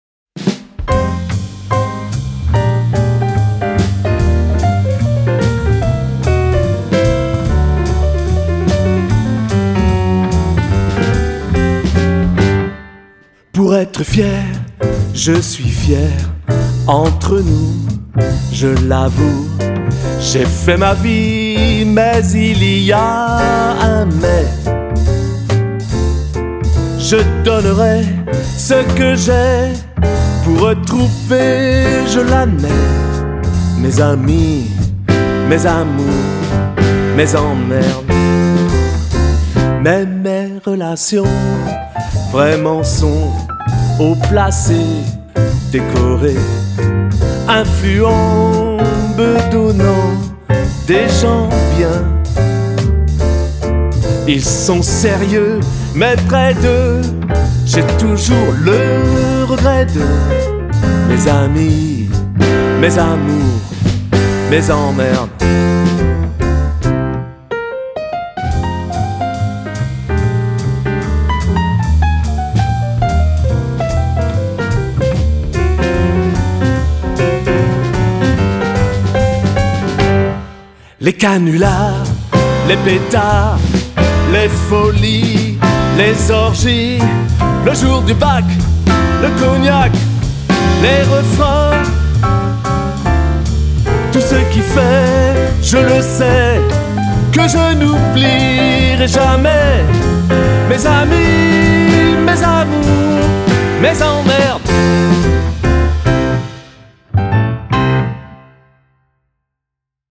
Französische Chansons & stilvoller Jazz
Piano & Gesang
Schlagzeug
Kontrabass